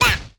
Sfx Player Uppercut Sound Effect
sfx-player-uppercut-2.mp3